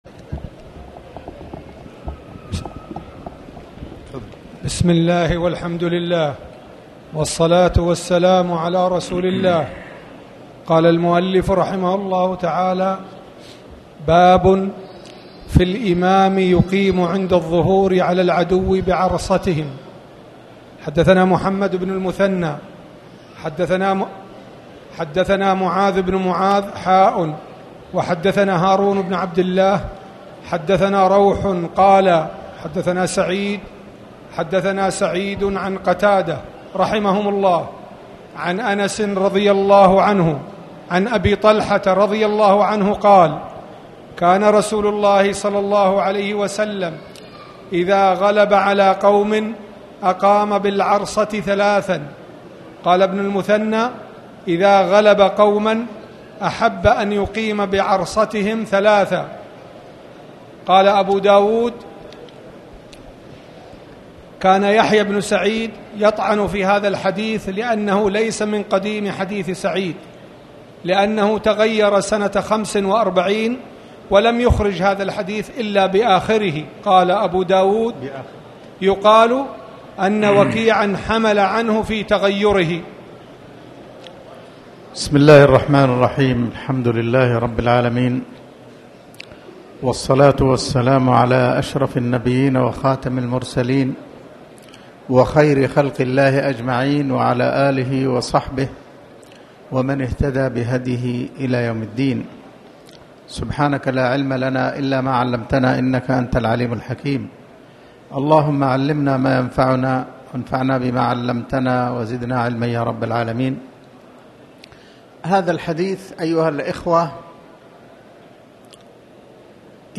تاريخ النشر ١١ ربيع الأول ١٤٣٩ هـ المكان: المسجد الحرام الشيخ